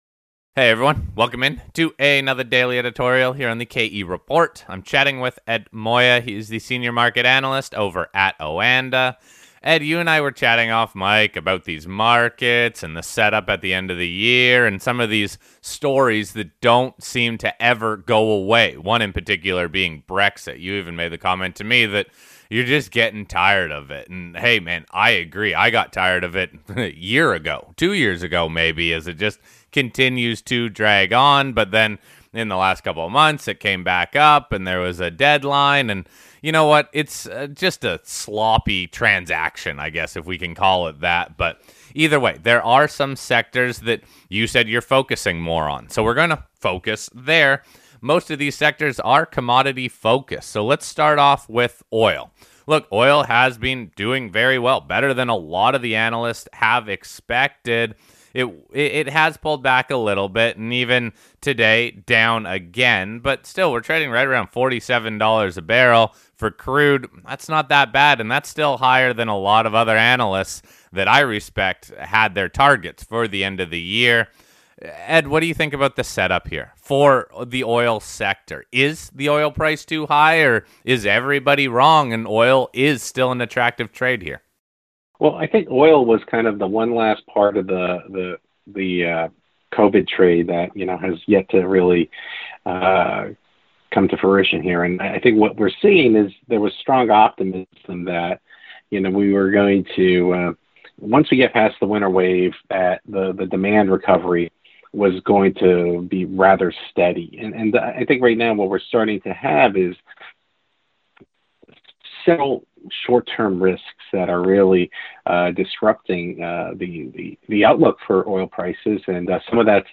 This interview was recorded yesterday so all the price reference are from late in the trading day yesterday.